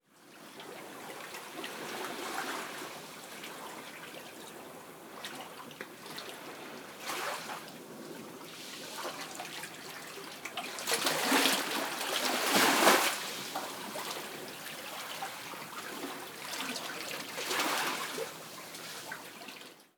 seawaves_indoors